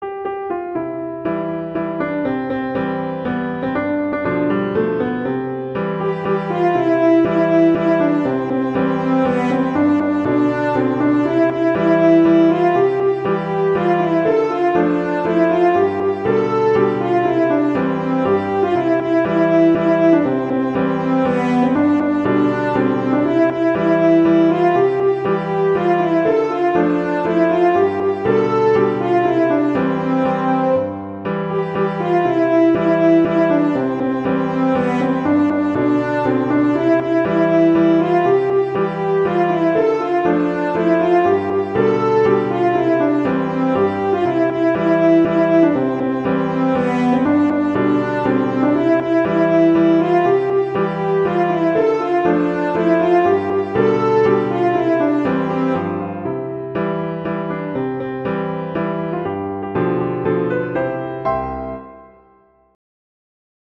arrangements for horn and piano
traditional, irish, children